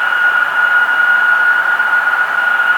Add external IAE sounds
v2500-lowspool.wav